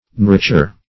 nouriture - definition of nouriture - synonyms, pronunciation, spelling from Free Dictionary Search Result for " nouriture" : The Collaborative International Dictionary of English v.0.48: Nouriture \Nour"i*ture\, n. Nurture.
nouriture.mp3